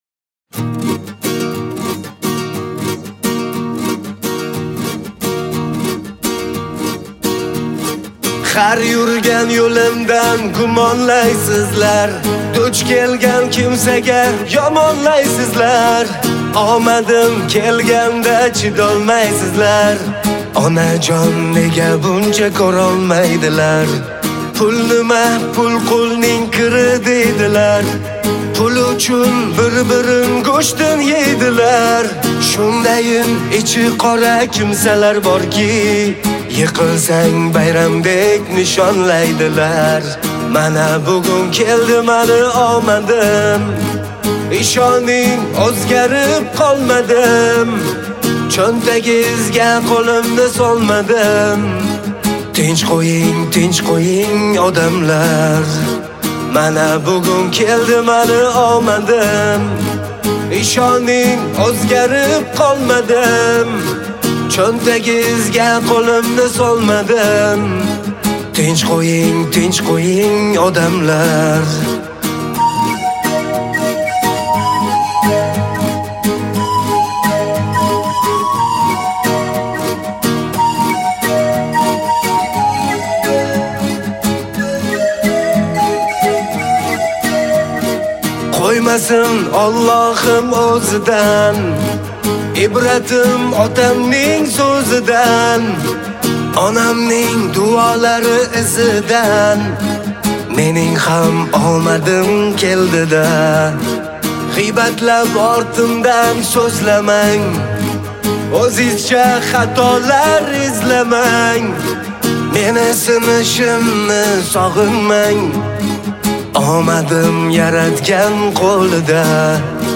guitar version